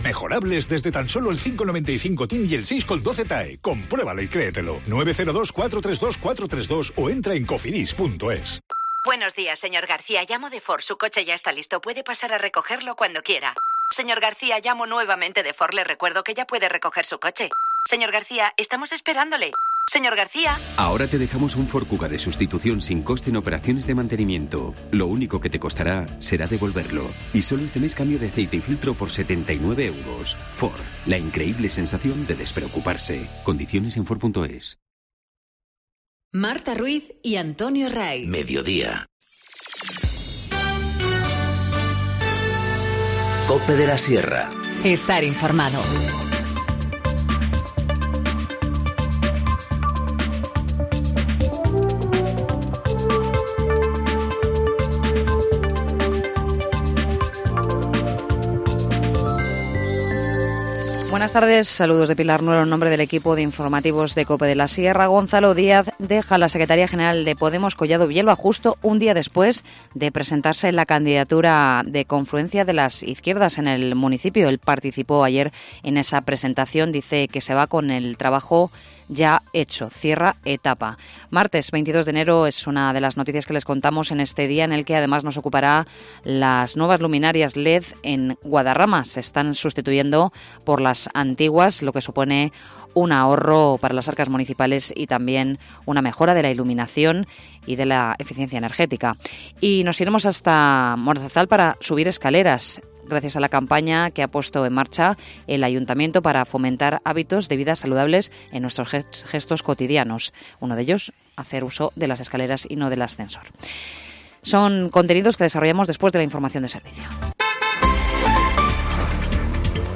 Informativo Mediodía 22 enero- 14:50h